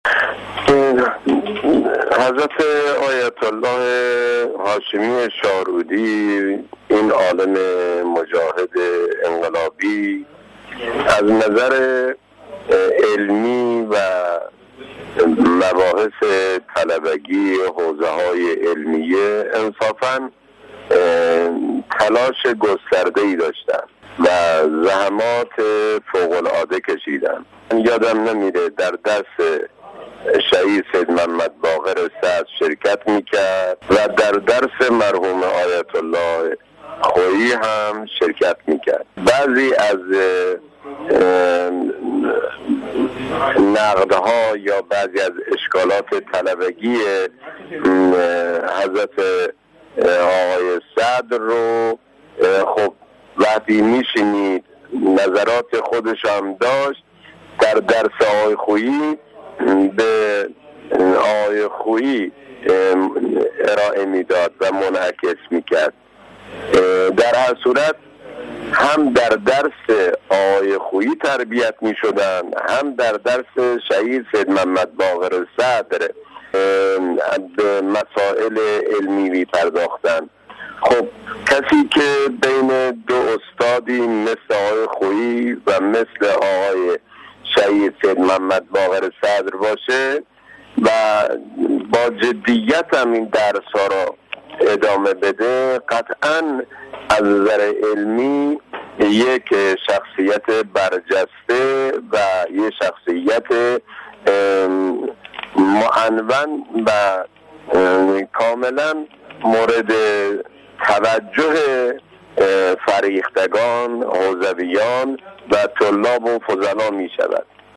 حجت‌الاسلام‌والمسلمین علی اسلامی نماینده مردم قزوین در گفت‌وگو با خبرنگار خبرگزاری رسا، با اشاره به شخصیت برجسته علمی مرحوم آیت‌الله هاشمی شاهرودی گفت: ایشان از دیدگاه علمی تلاش گسترده‌ای داشتند و در زمینه‌های مختلف علمی زحمات فوق‌العاده‌ای کشیدند.